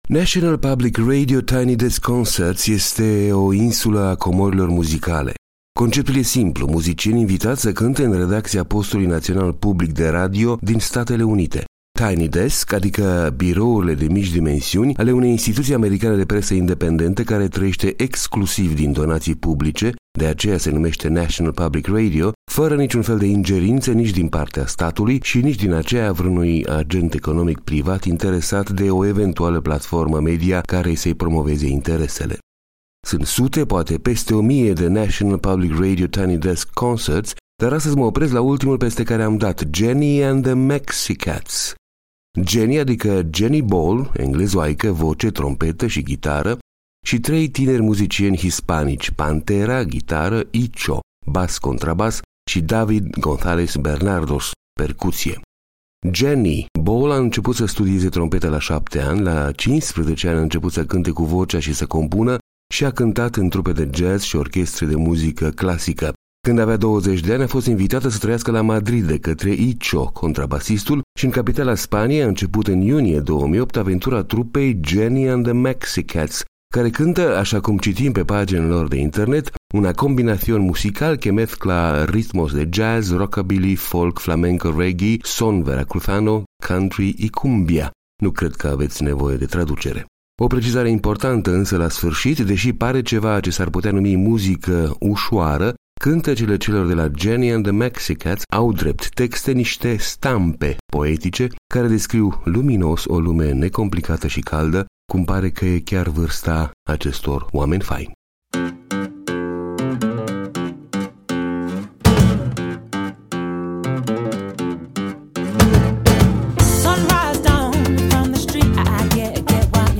O englezoaică și trei tineri muzicieni hispanici.
voce, trompetă, ghitară
bas, contrabas
percuție